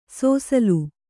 ♪ sōsalu